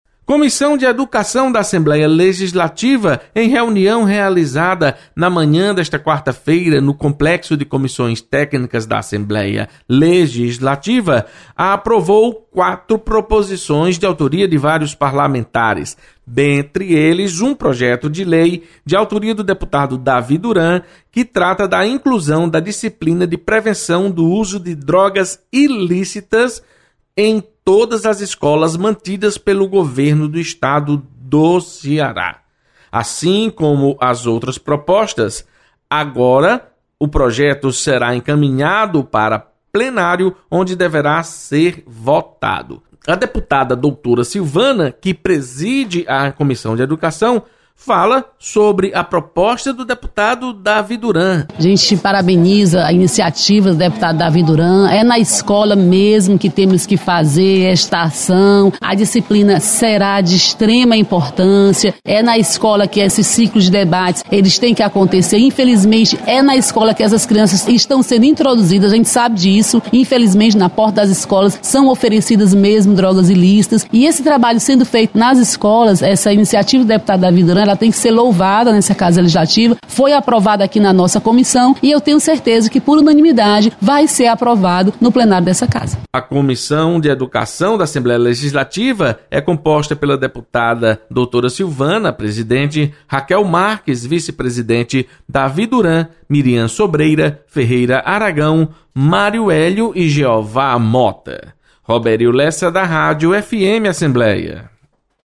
Comissão de Educação aprova projeto que insere disciplina de combate às drogas nas escolas públicas. Repórter